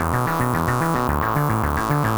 Index of /musicradar/8-bit-bonanza-samples/FM Arp Loops
CS_FMArp A_110-E.wav